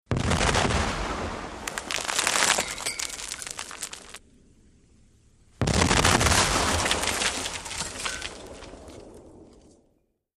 WEAPONS - CANNON CIVIL WAR CANNON: EXT: 10 lb. parrot cannons firing two canisters, debris spread with metal bar impacts.